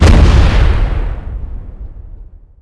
开火.wav